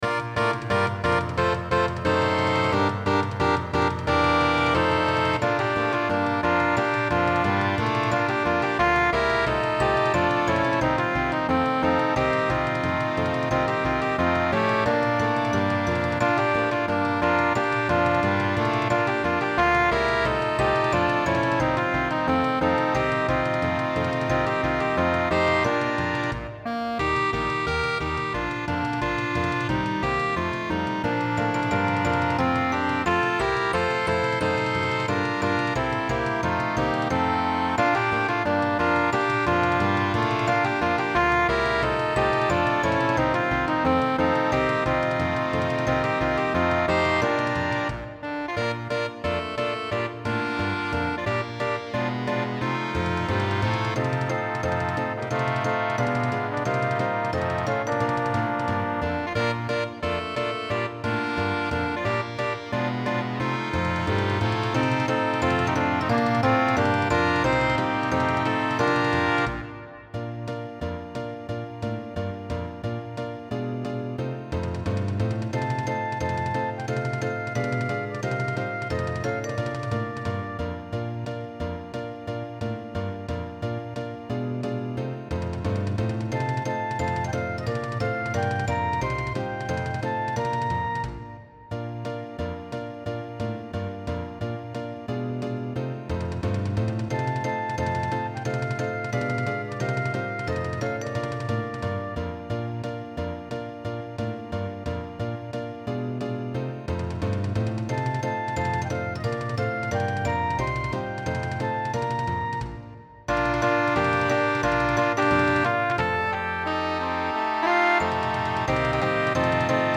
ORCHESTRA DIDATTICA